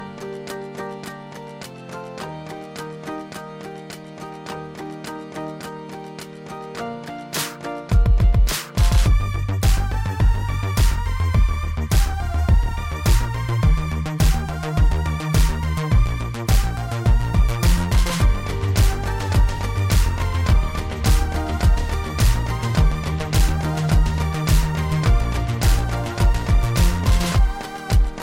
Musical AI – Musica ed intelligenza artificiale